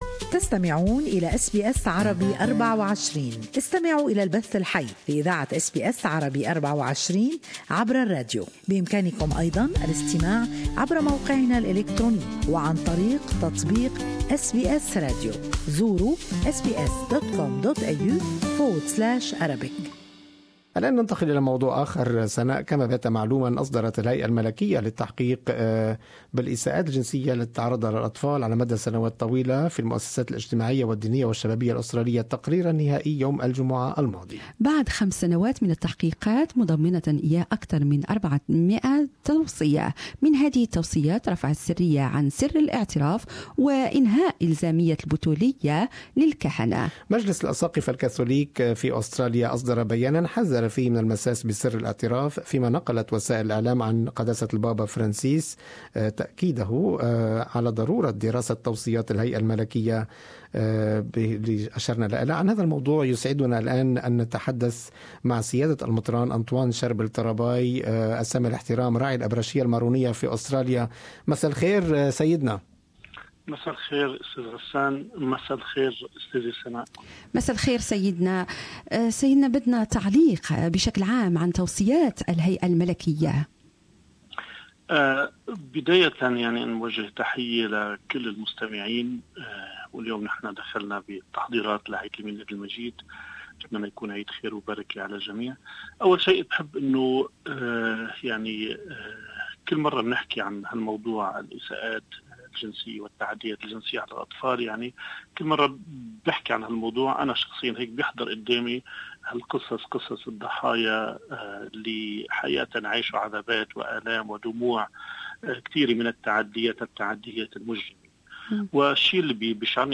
There is no evidence that links celibacy to child abuse, says Maronite Bishop of Australia Antoine Charbel Tarabay. More in this interview.